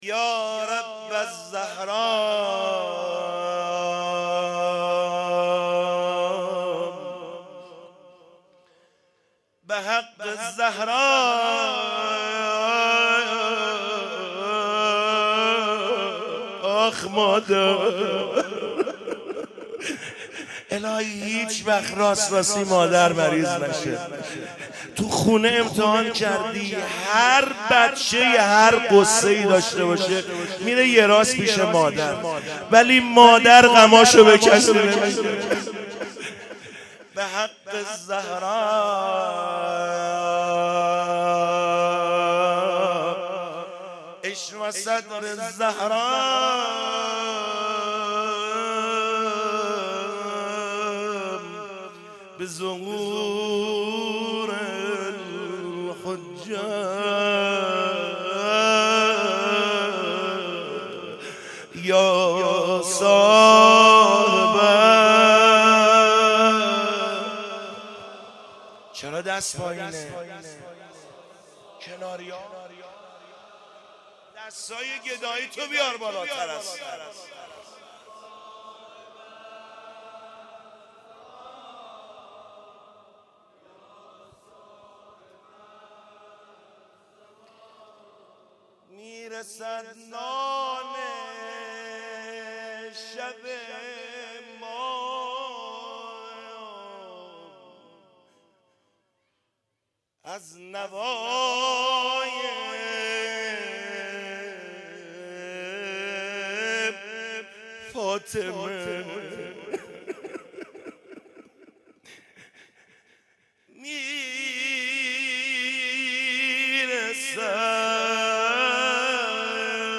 مراسم فاطمیه اول ۹۶